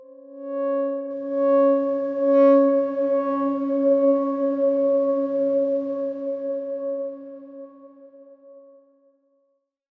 X_Darkswarm-C#4-mf.wav